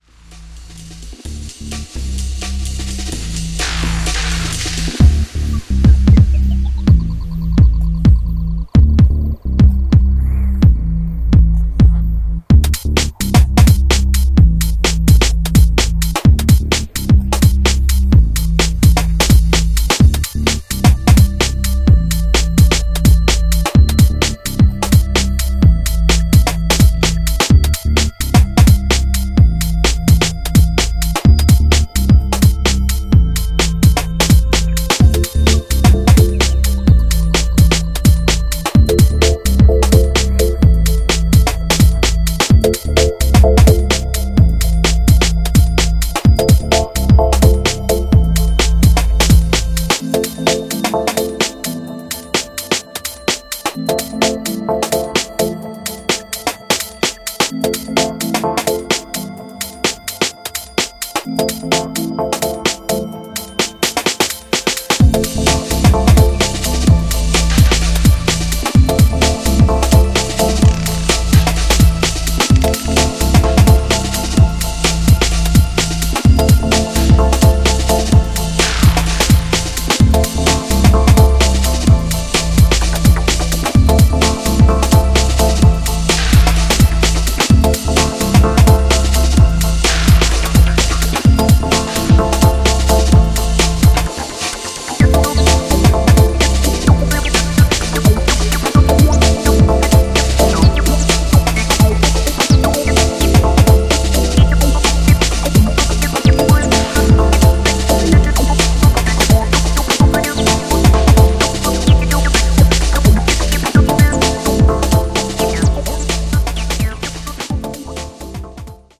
channels deep, chugging house,
sun-kissed 2-step and introspective rollers